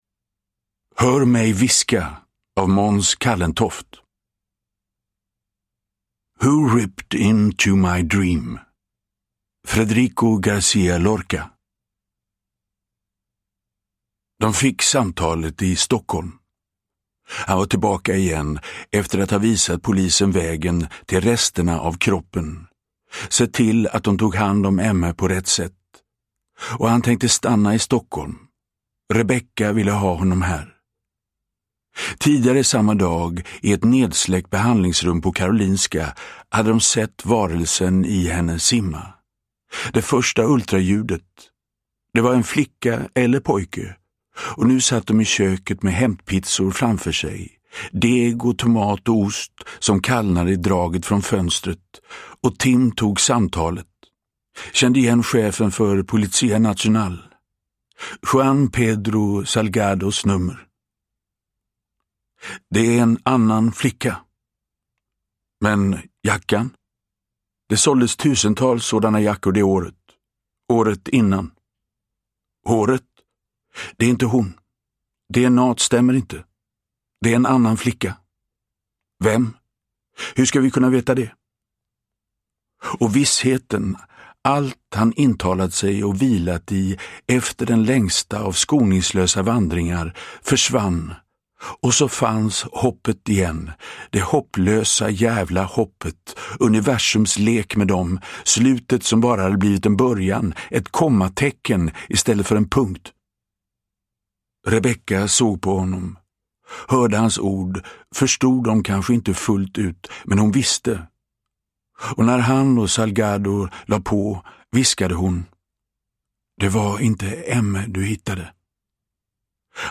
Uppläsare:
Magnus Roosmann
Hedda Stiernstedt
Ljudbok